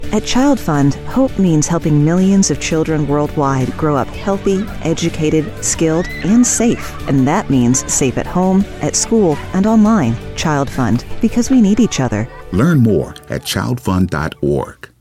Broadcast-quality PSAs available at no cost to your station.